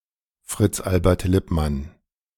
Fritz Albert Lipmann (German pronunciation: [fʁɪts ˈʔalbɛʁt ˈlɪpman]
De-Fritz_Albert_Lipmann.ogg.mp3